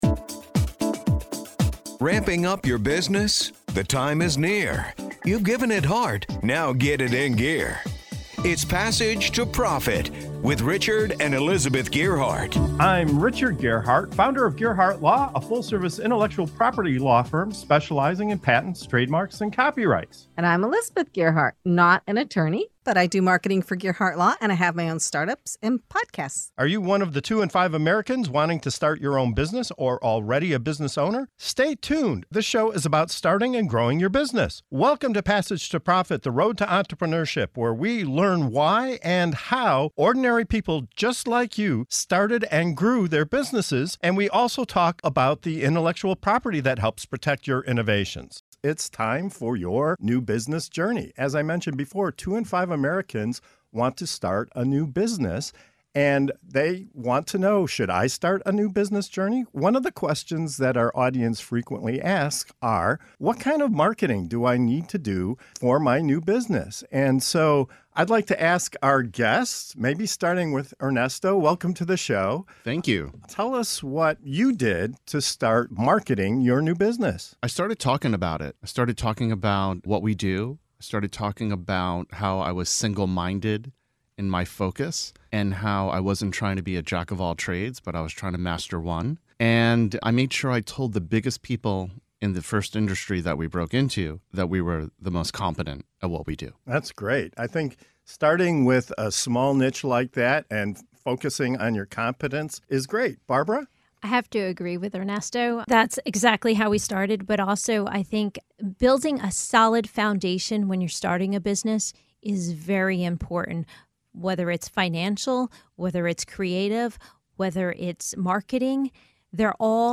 Join us as we dive into the world of starting a new business with insights from successful entrepreneurs on their marketing strategies. From focusing on niche markets and building strong foundations to leveraging local chambers of commerce and evolving digital marketing tactics, our guests share their personal journeys and practical tips.